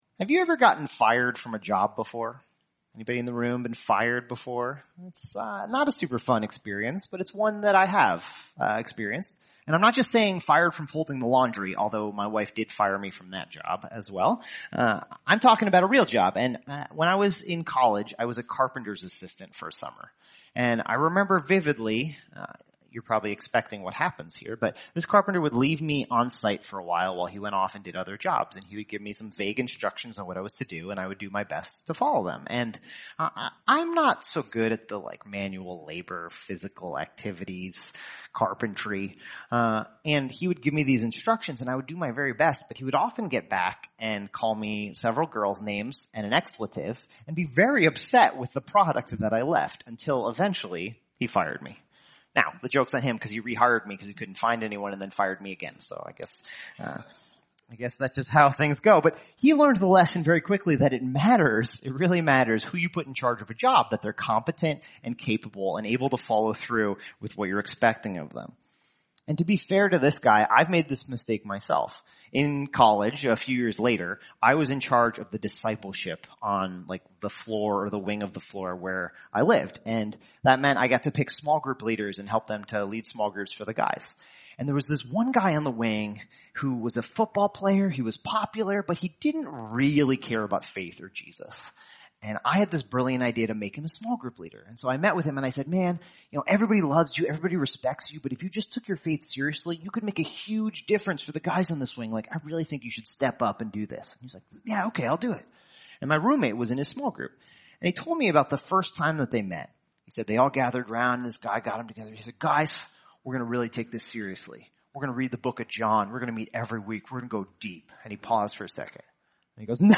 Stewarding Your Vote Politics Watch Message By